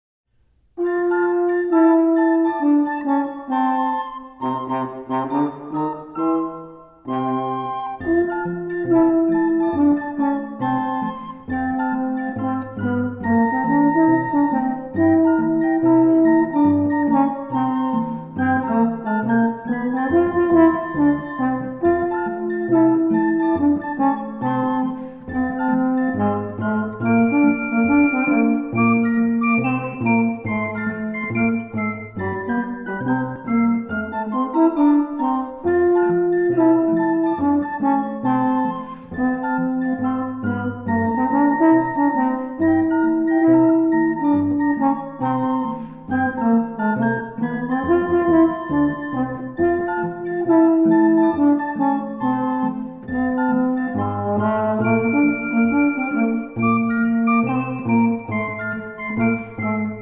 A Renaissance Band
sackbut, recorders
cornetto, gemshorns, recorders
percussion, viol, recorders
vielle, viols